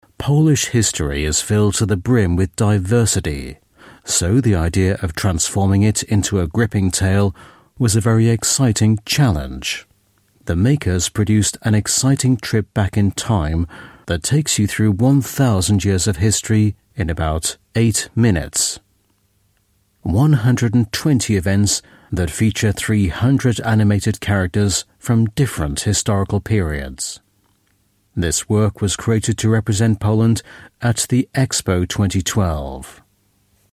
Dokus
Native Speaker